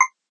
ping_5.ogg